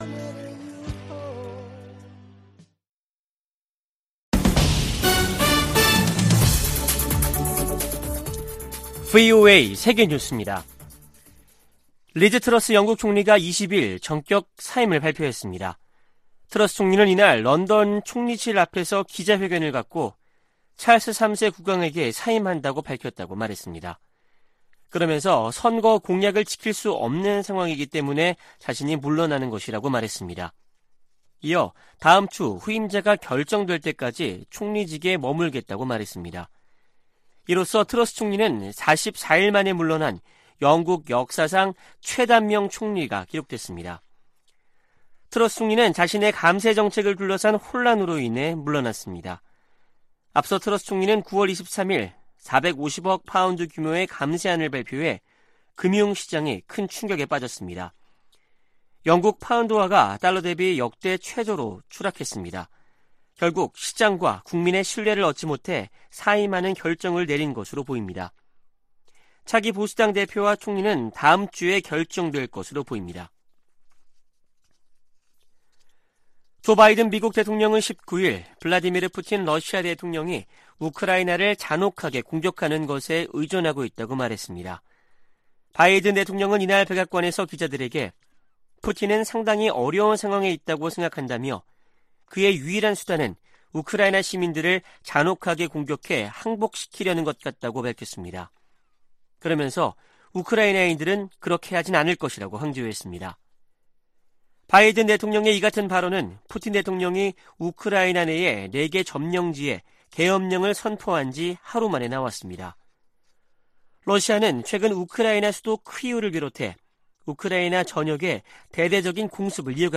VOA 한국어 아침 뉴스 프로그램 '워싱턴 뉴스 광장' 2022년 10월 21일 방송입니다. 미 국무부는 연이은 북한 포 사격에 심각한 우려를 나타내며 한국과 일본에 악영향을 줄 수 있다고 지적했습니다. 미 공군 전략폭격기 B-1B가 괌에 전개됐다고 태평양공군사령부가 확인했습니다. 한국의 다연장 로켓 구매 계약을 체결한 폴란드는 러시아의 침공을 저지하기 위해 이 로켓이 필요하다고 설명했습니다.